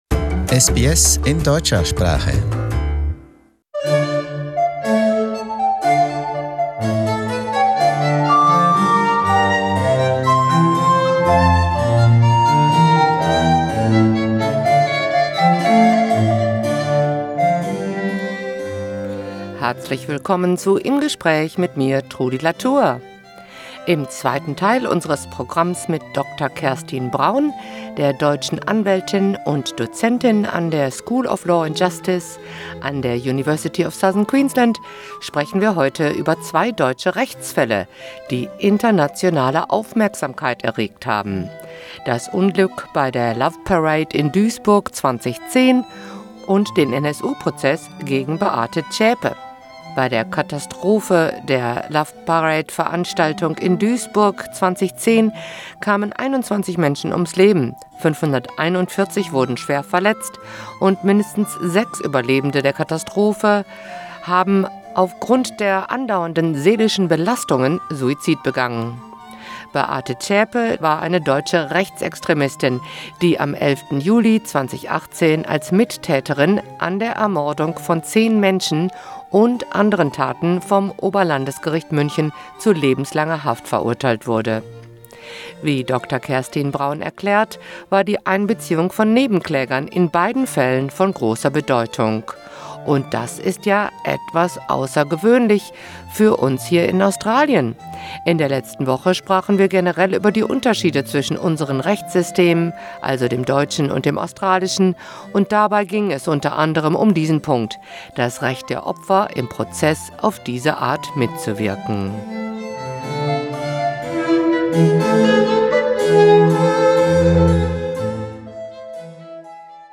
In conversation: Loveparade and Beate Zschäpe